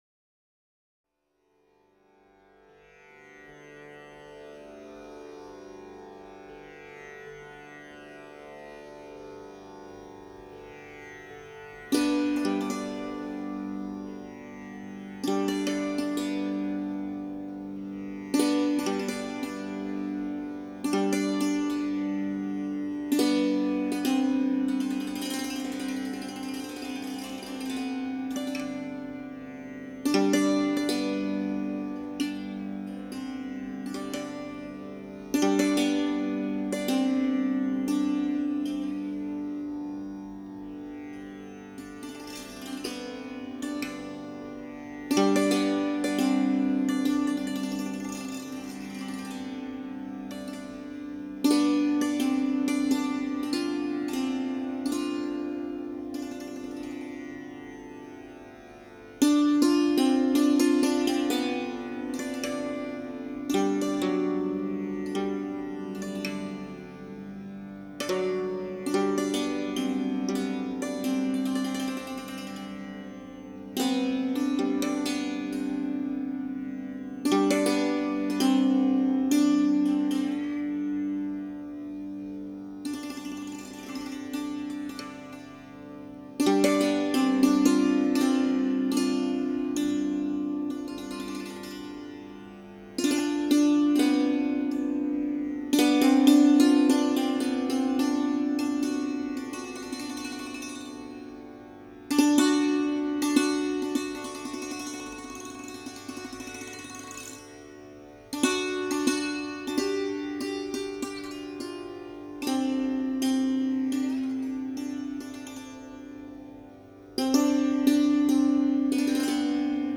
An ingenious evening raga
santoor